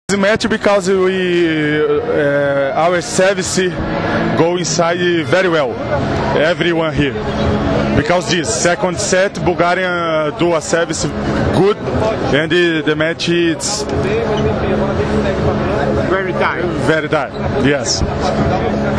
IZJAVA EVANDRA GVERE